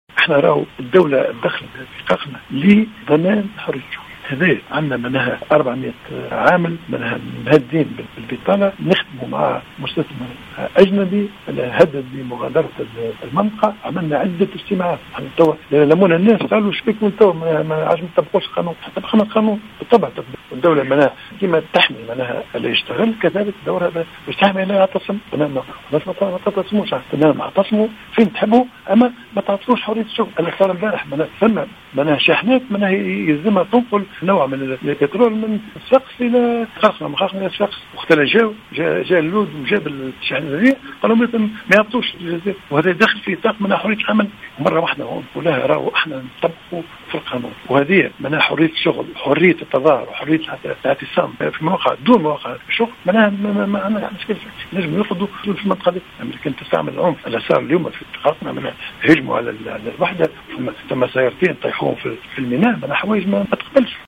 أكد رئيس الحكومة الحبيب الصيد في تصريح لمراسل الجوهرة أف أم على هامش زيارته اليوم الى ولاية الكاف ان الدولة تدخلت في جزيرة قرقنة لضمان حرية الشغل، لنحو 400 عامل مهددين بالبطالة في حال تنفيذ المستثمر الأجنبي لتهديداته بمغادرة البلاد.